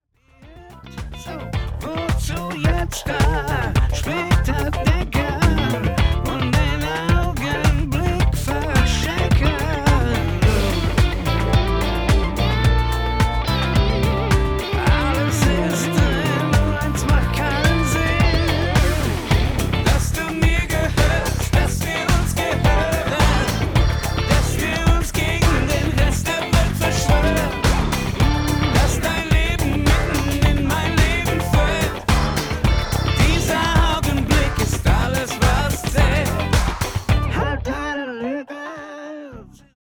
Die Beispiele sind sorgfältig auf gleiche Lautheit eingestellt, damit Sie auch wirklich die Klangqualitäten und nicht die Lautheit beurteilen.